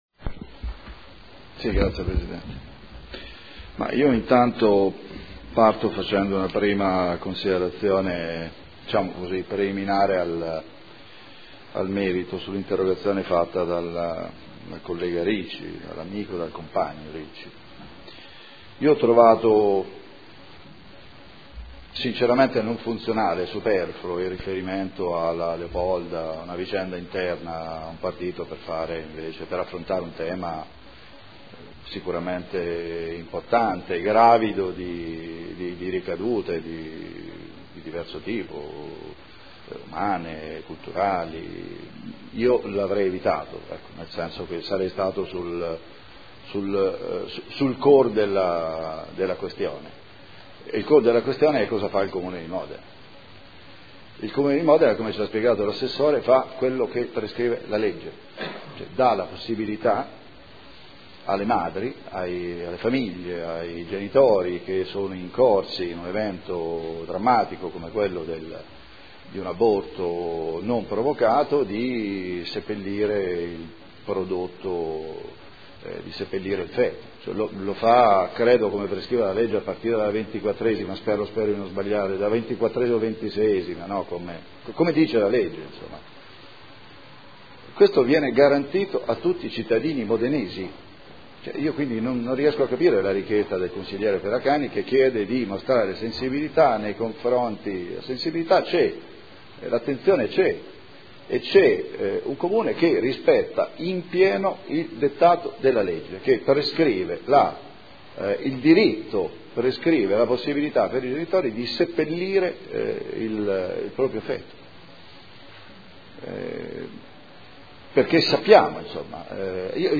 Seduta del 16 gennaio. Interrogazione del gruppo consiliare SEL avente per oggetto: “Il cimitero dei feti” – Primo firmatario consigliere Ricci.